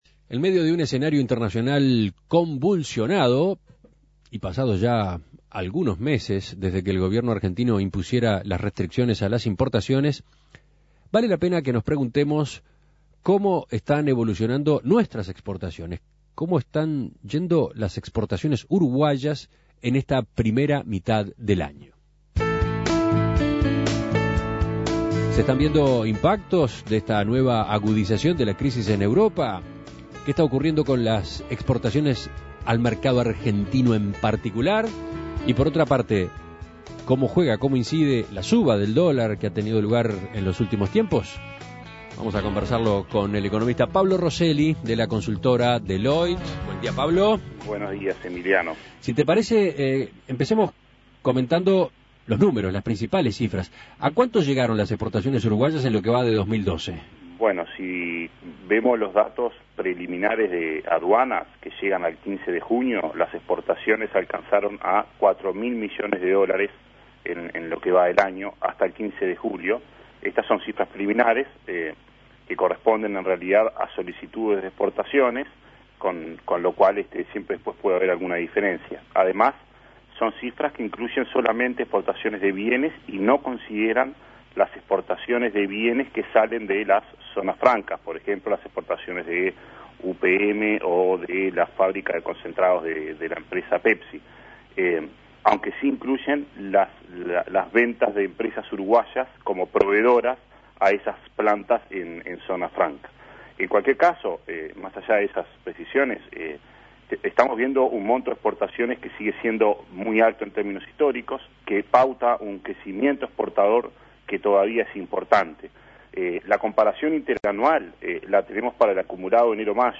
Análisis Económico Evolución de las exportaciones uruguayas en lo que va del 2012